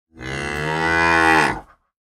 دانلود آهنگ ما ما گاو در طویله از افکت صوتی انسان و موجودات زنده
دانلود صدای ما ما گاو در طویله از ساعد نیوز با لینک مستقیم و کیفیت بالا
جلوه های صوتی